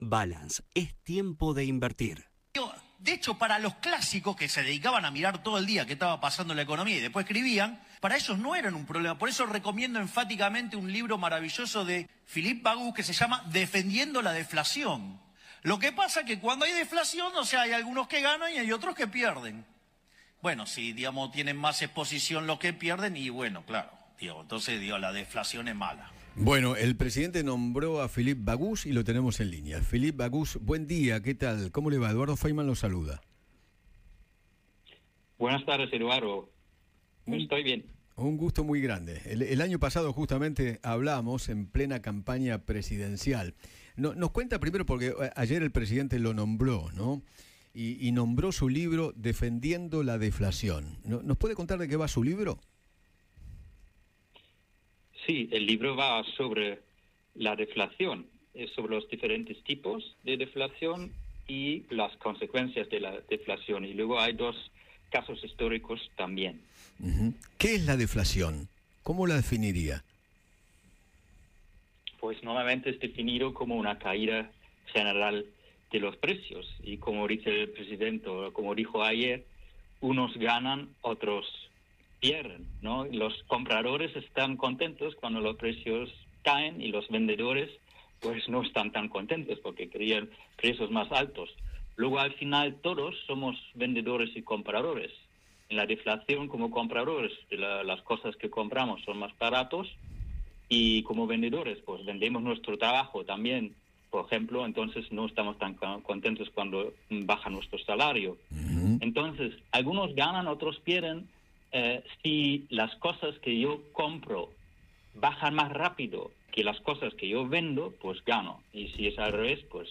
El economista de Phillipp Bagus conversó con Eduardo Feinmann sobre la deflación y analizó la gestión de Javier Milei.